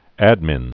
(ădmĭn)